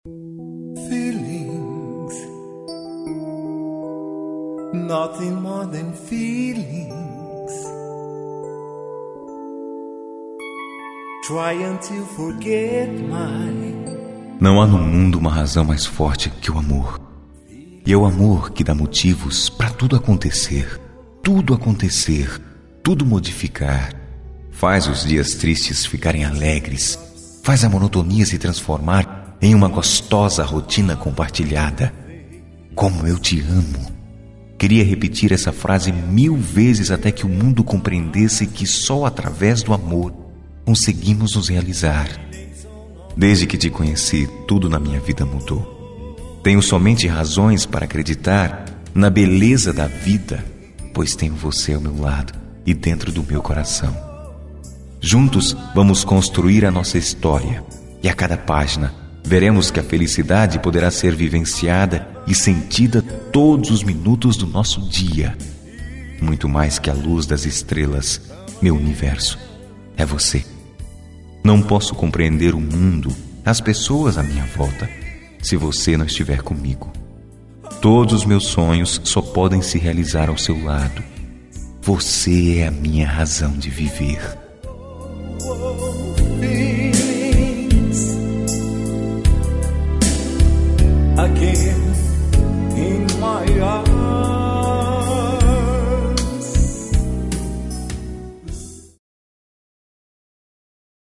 Romântica I | Voz Masculina
Mensagens Fonadas